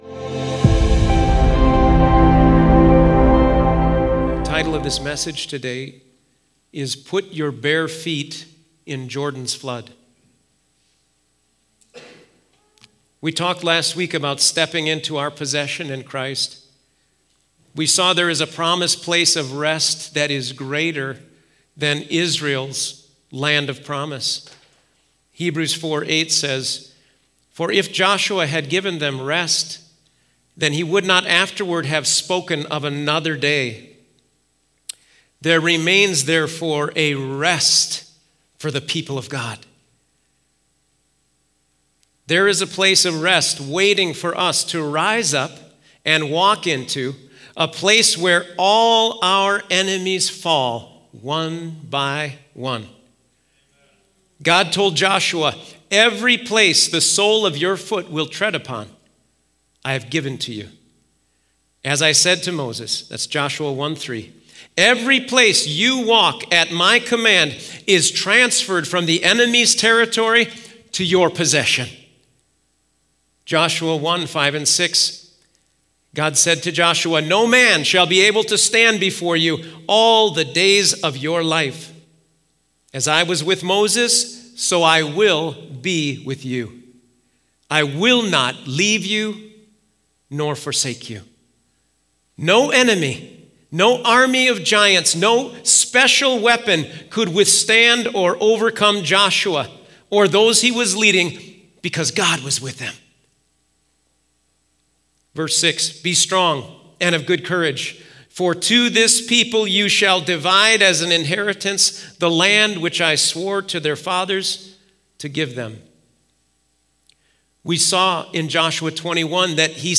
Sermons | Auxano Church